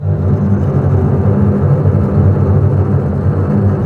Index of /90_sSampleCDs/Roland LCDP08 Symphony Orchestra/STR_Cbs Bow FX/STR_Cbs Tremolo